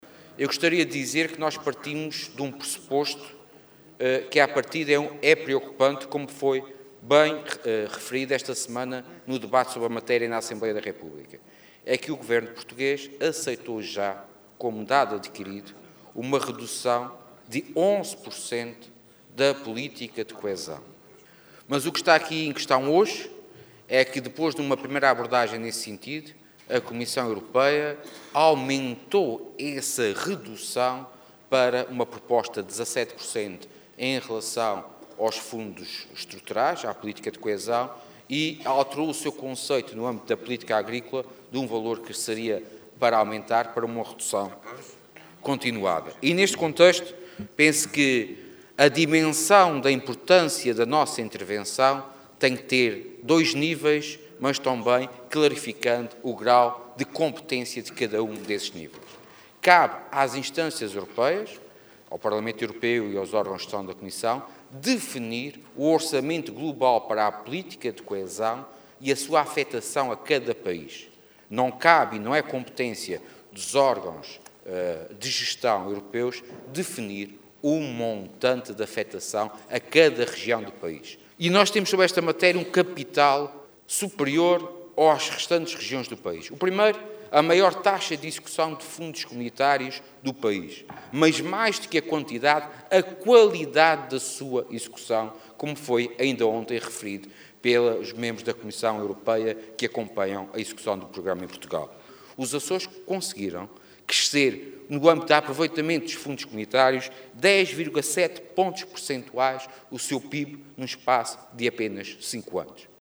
O governante, que falava na sessão desta tarde da Assembleia Legislativa dos Açores – no decurso da discussão do programa do XI Governo Regional – defendeu que, no contexto da utilização de fundos comunitários, os Açores têm um capital superior ao das restantes regiões do país.